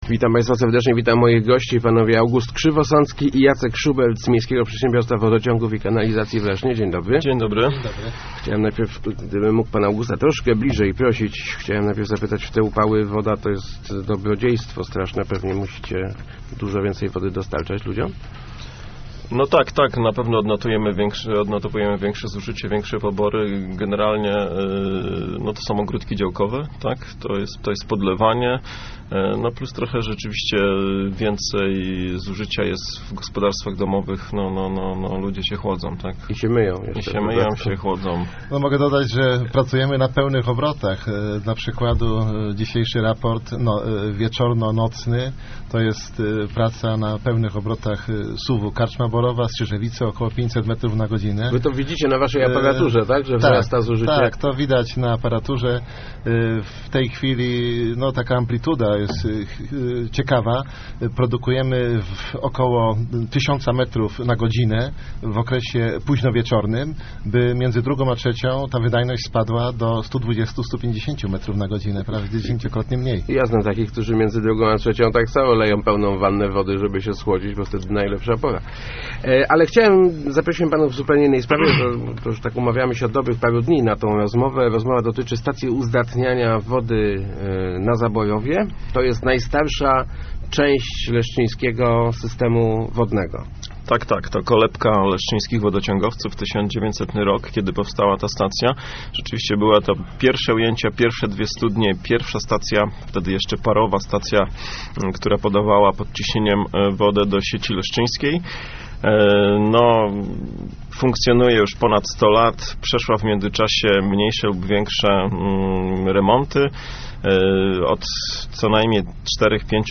Do końca przyszłego roku stacja uzdatniania wody na Zaborowie zostanie zmodernizowana - mówili w Rozmowach Elki